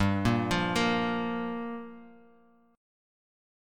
Bb5/G chord